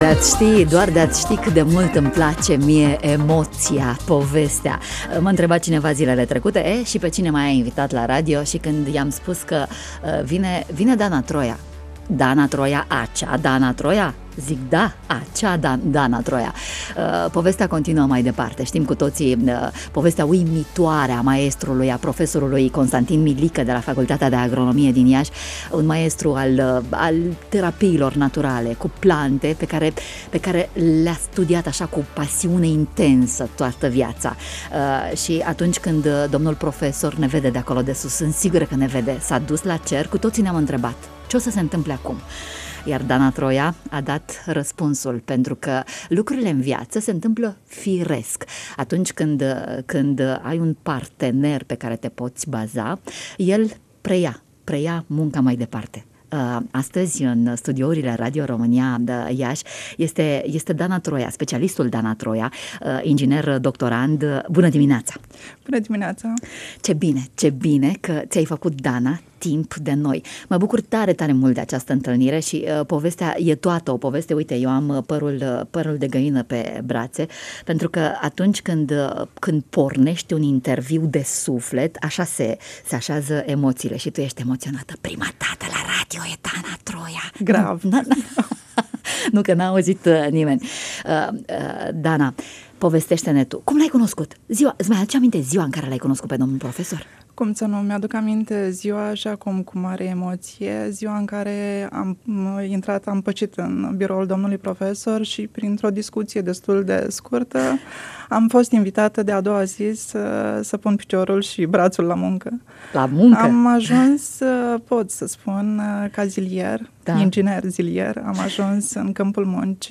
În portofoliul Herbalrom (Aroma Iaşi) există reţete pentru peste 200 de afecţiuni, dintre cele mai căutate fiind cele pentru afecţiunile cardiovasculare, tumorale, metabolice şi digestive. Mai multe detalii în interviul de mai sus.